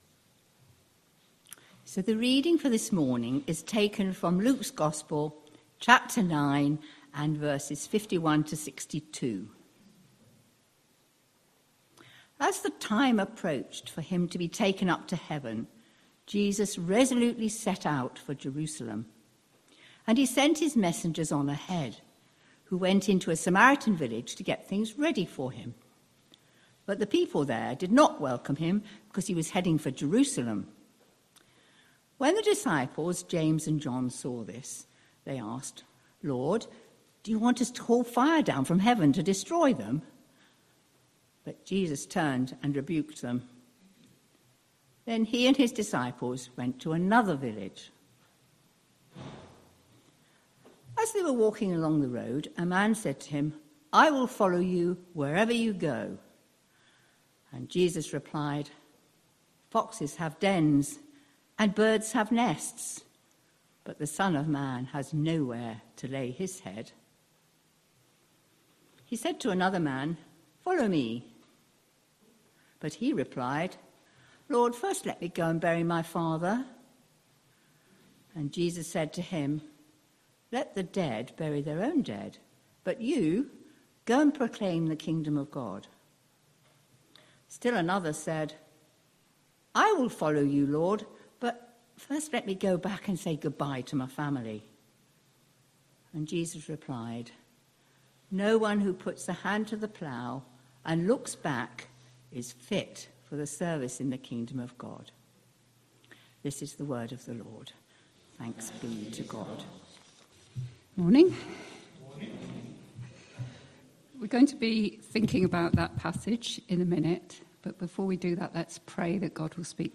29th June 2025 Sunday Reading and Talk - St Luke's